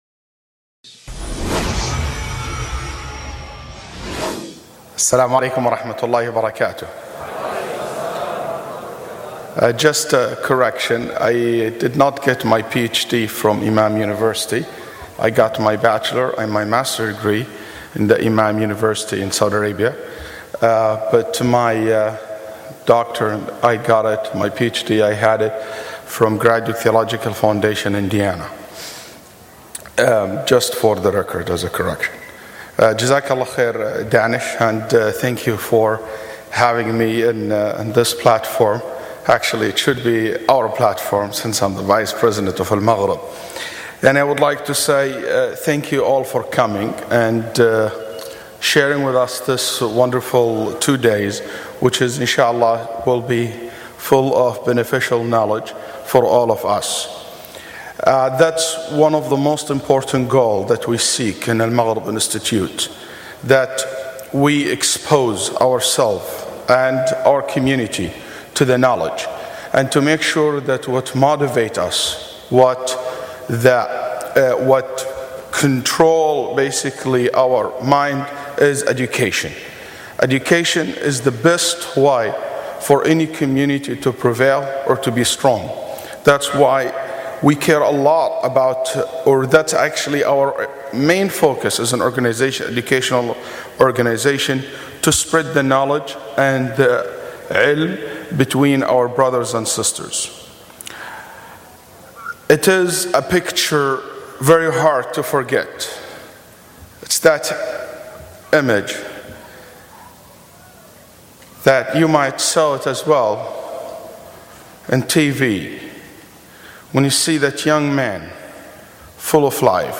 addresses the current terrorism and jihad at AlMaghrib Institute’s IlmFest 2009.